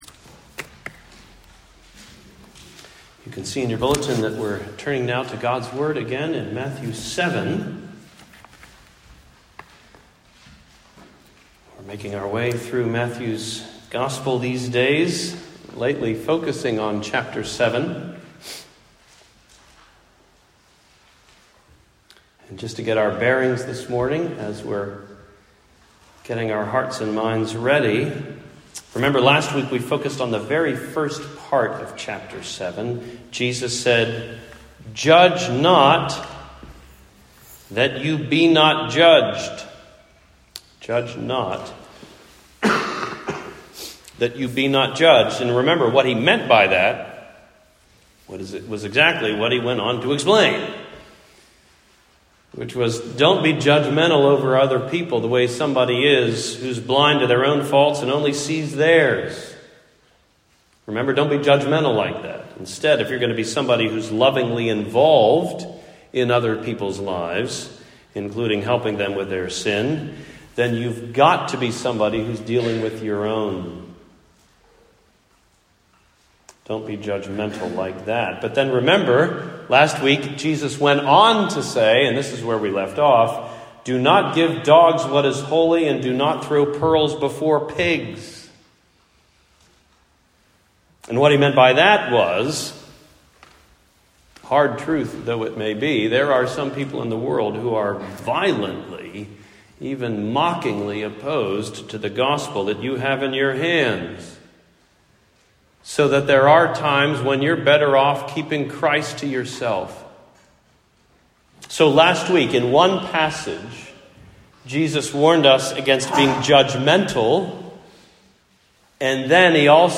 Ask, Seek, Knock, Love: Sermon on Matthew 7:7-12